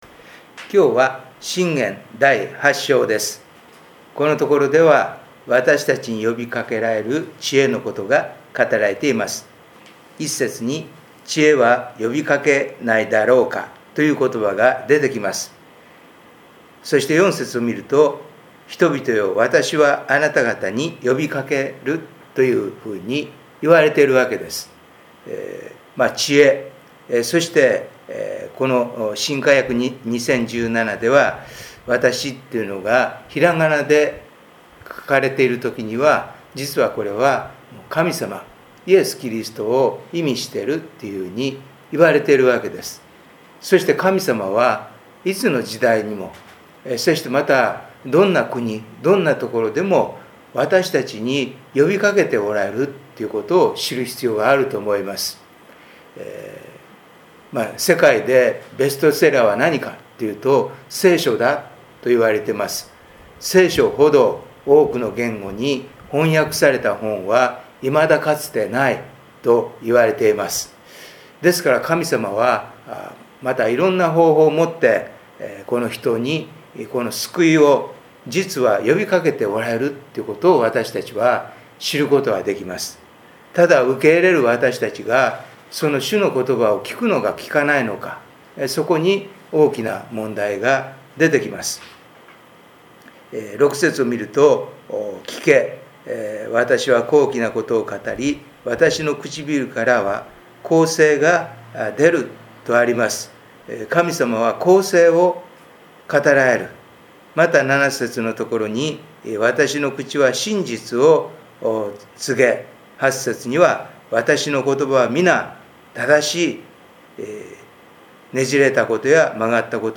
礼拝メッセージ│日本イエス・キリスト教団 柏 原 教 会